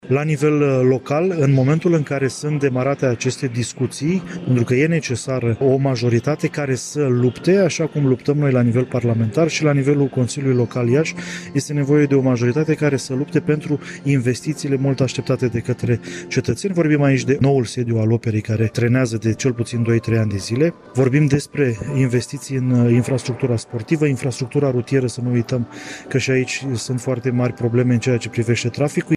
În ceea ce privește crearea unei majorități pentru Consiliul Local și cel Județean Iași, deputatul Bogdan Cojocaru, fost prefect, a declarat că PSD este deschis formării unei alianțe care să pledeze pentru proiectele Iașiului.